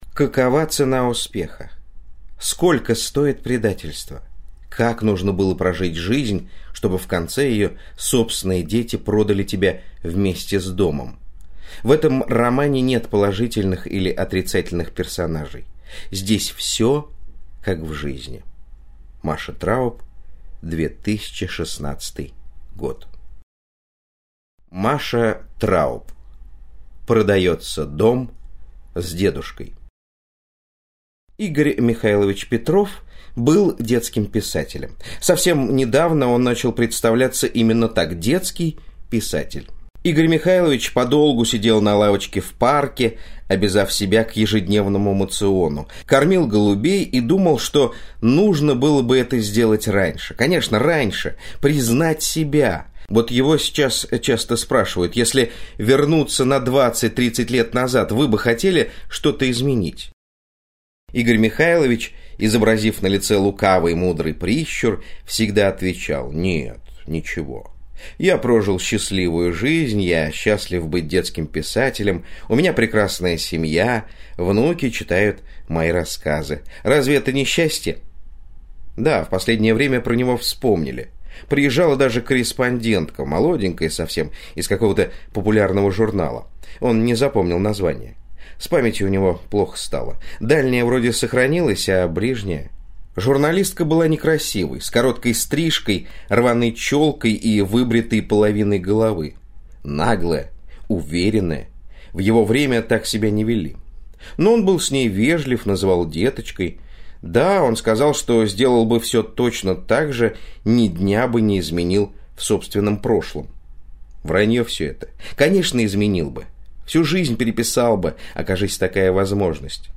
Аудиокнига Продается дом с дедушкой | Библиотека аудиокниг